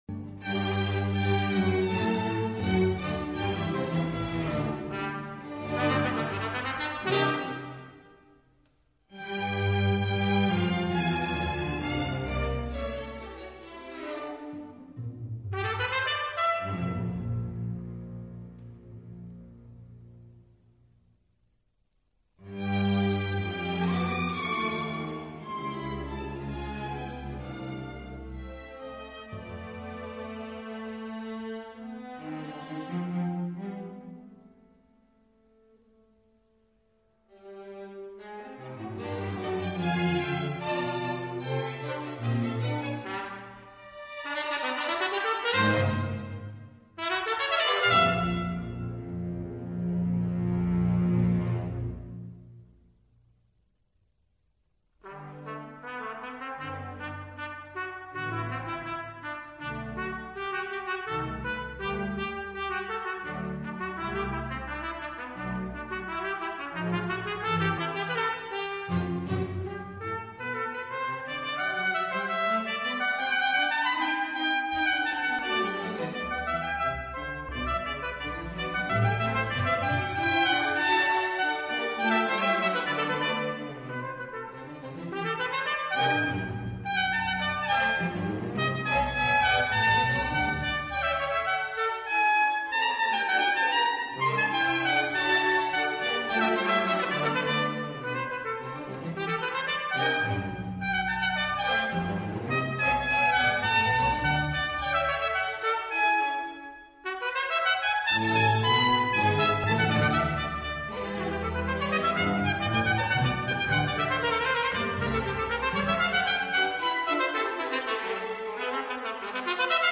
trumpet och stråkar/stråkkvintett, 114j, 1983, 9'